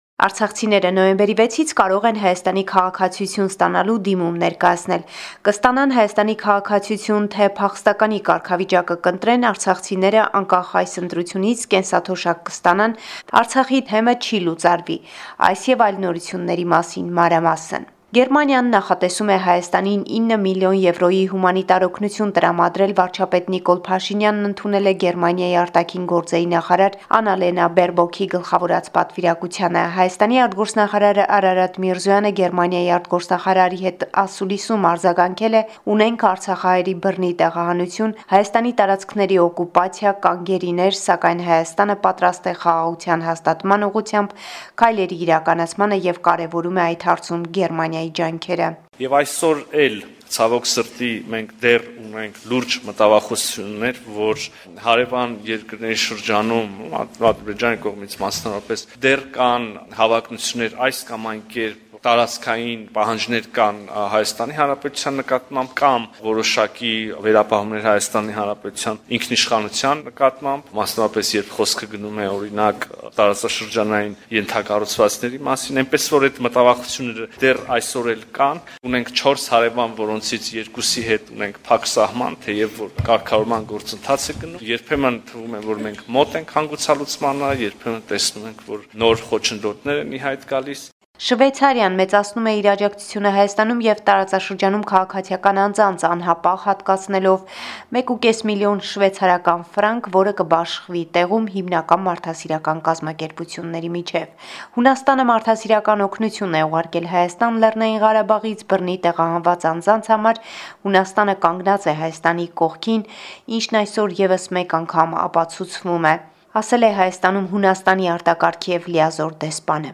Latest News from Armenia – 7 November 2023